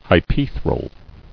[hy·pae·thral]